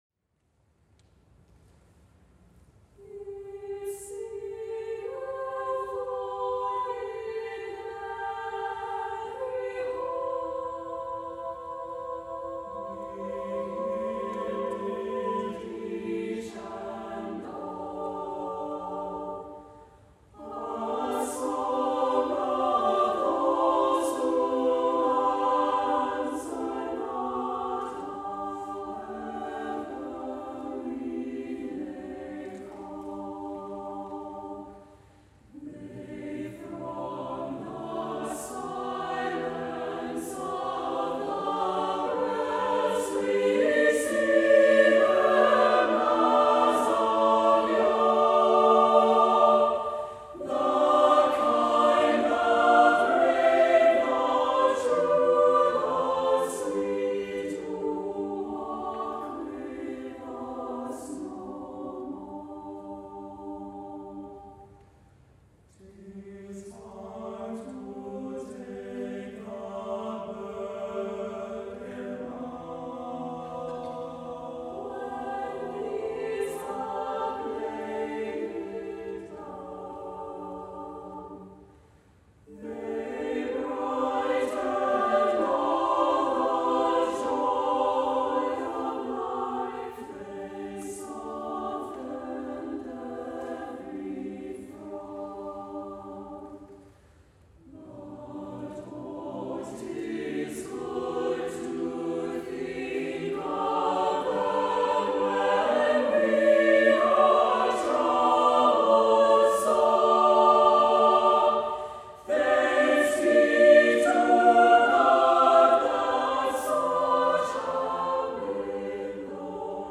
SATB a cappella chorus with divisi